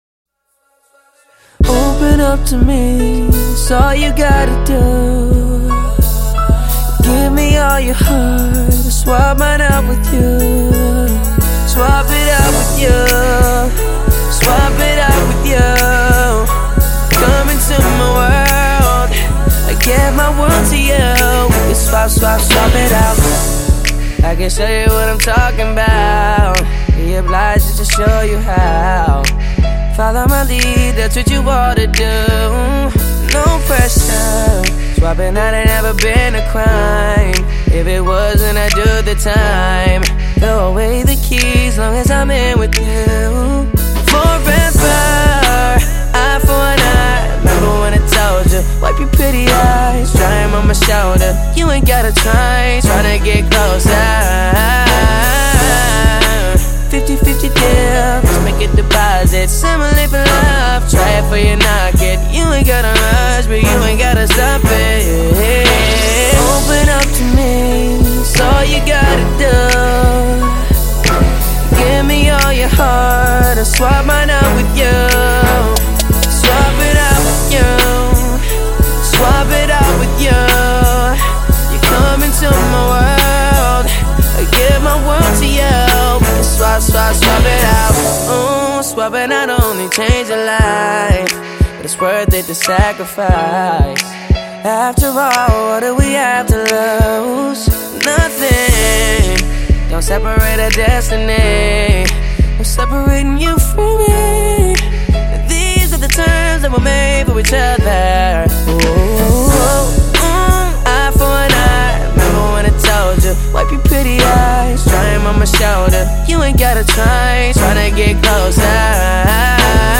Категория: Популярная музыка